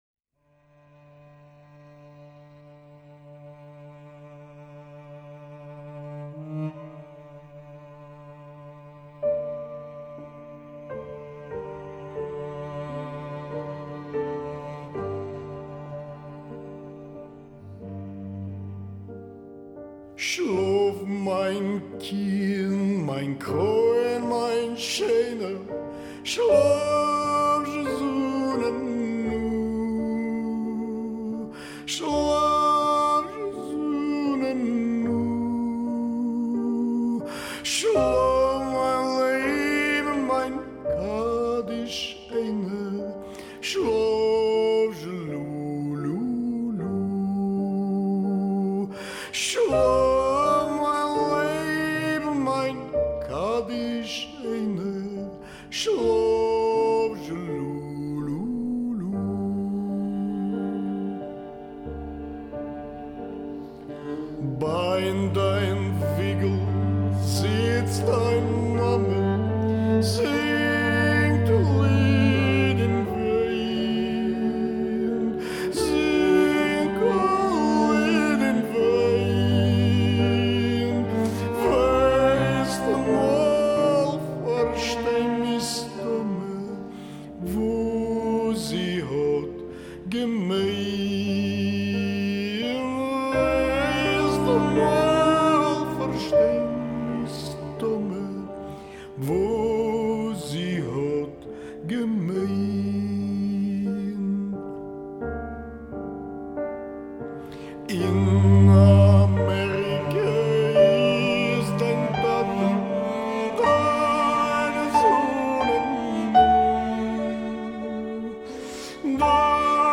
CD per bambini
che hanno partecipato cantando: